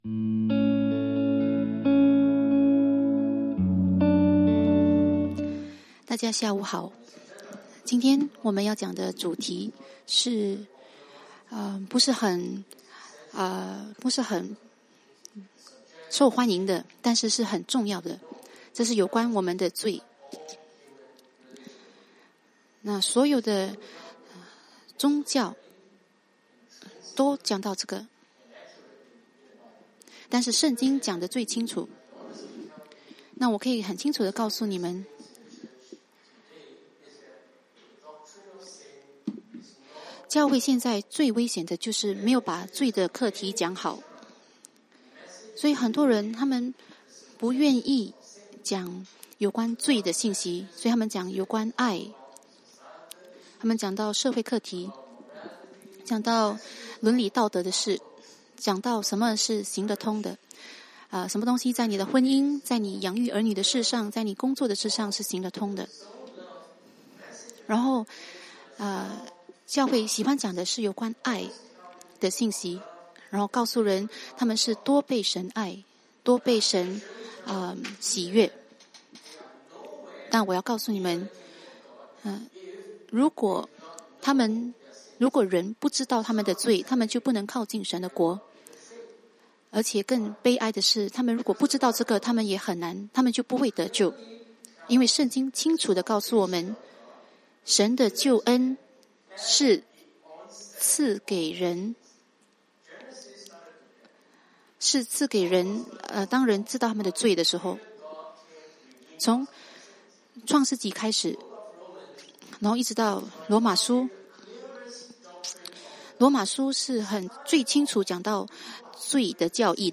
认识我们的罪（中文翻译）[12月5日英语崇拜]
中文音频